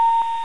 electronicpingshort.wav